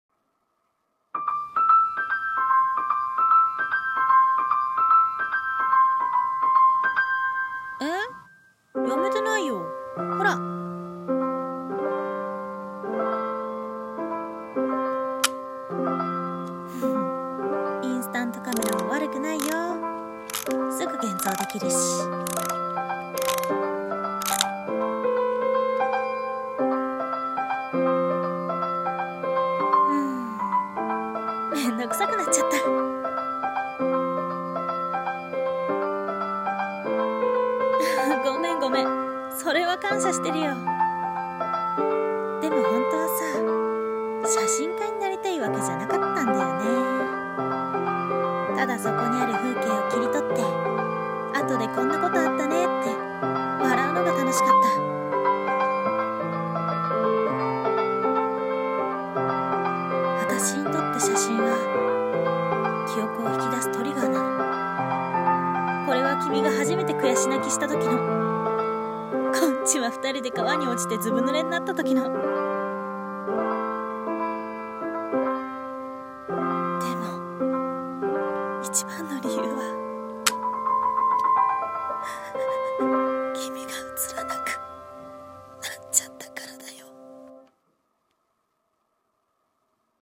心像【2人声劇】